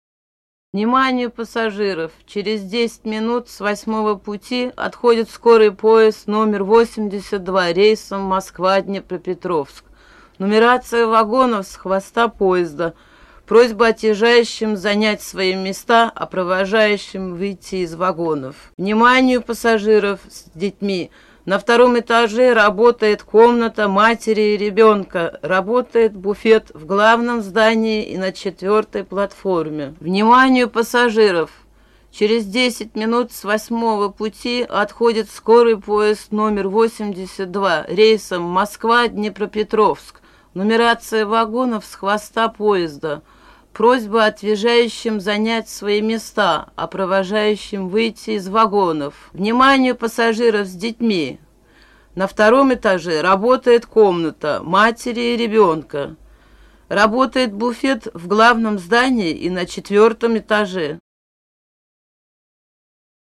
Russian Female Station Announcements. Not Loudspeaker.